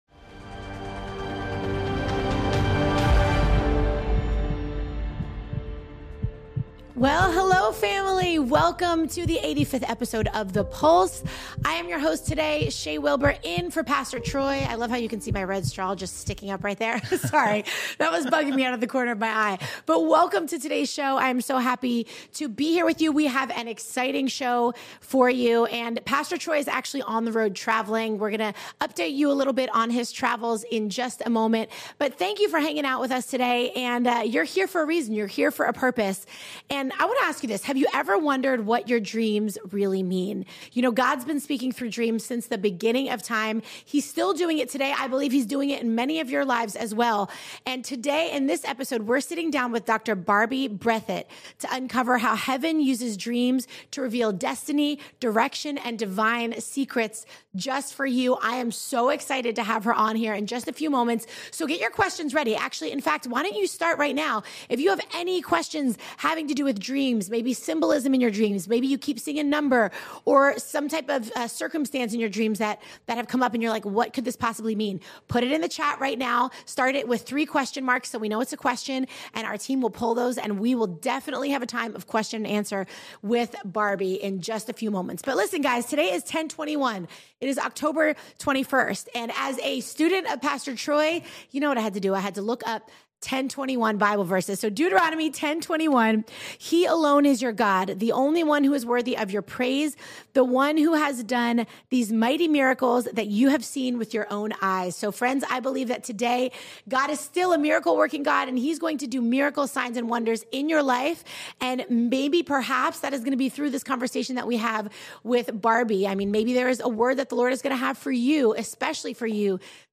our team sits down with prophetic teacher and dream interpreter